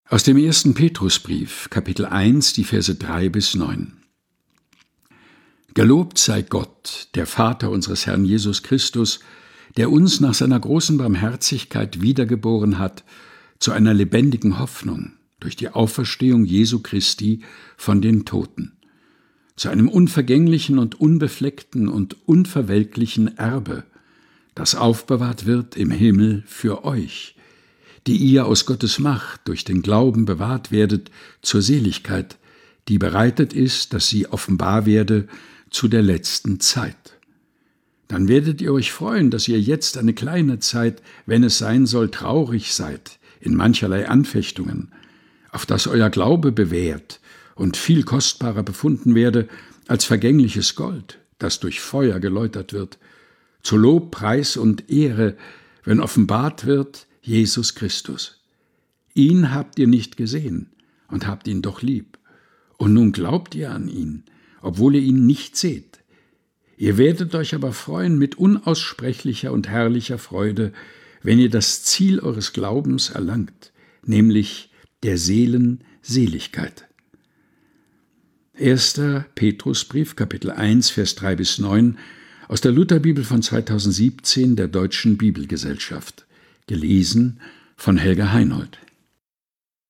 Predigttext zum Sonntag Quasimodogeniti 2025.